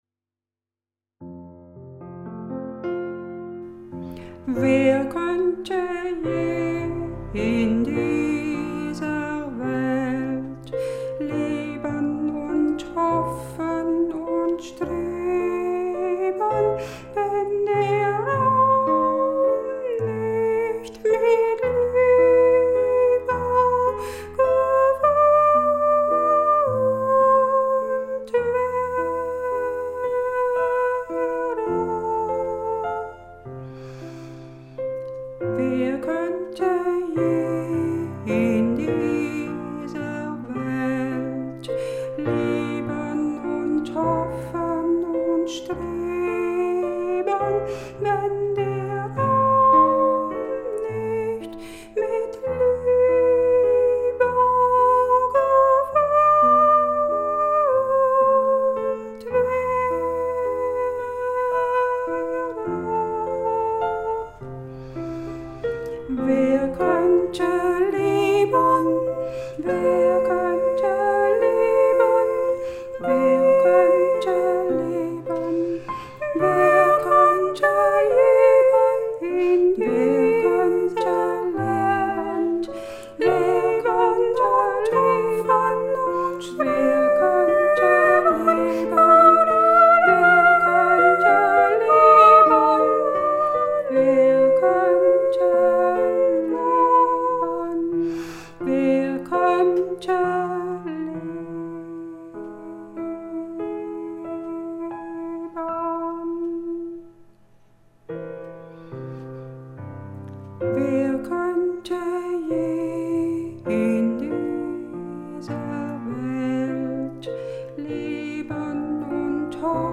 Besetzung: Sopran, Chor und Klavier